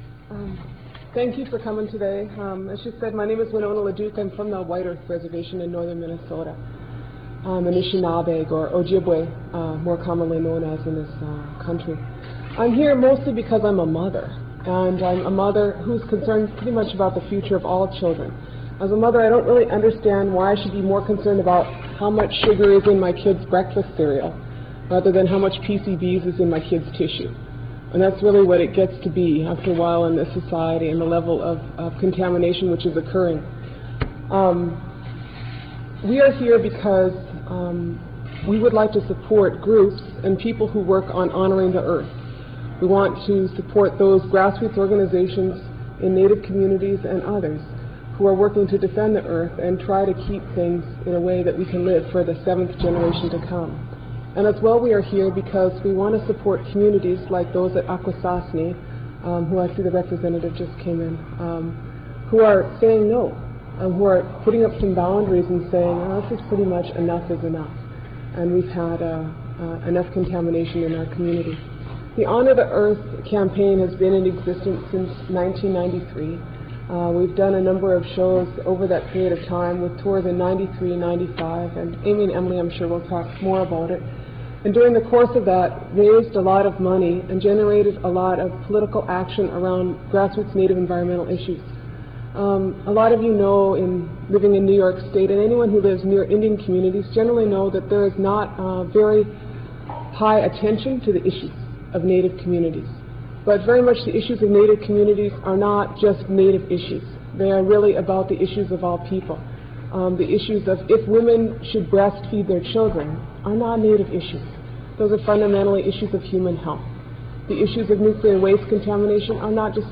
03. press conference - winona laduke (8:25)